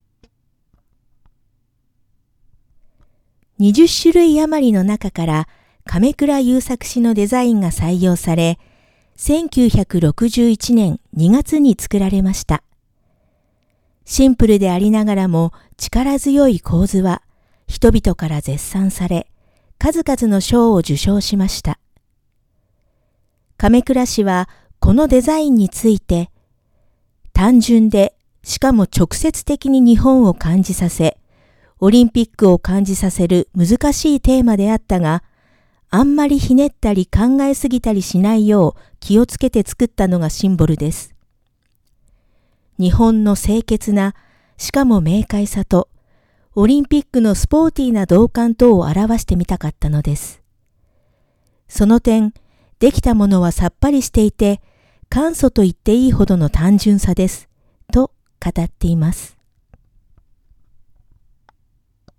主な展示品を音声で解説
メモリアルホール展示品の中から、主な物を音声で解説しています。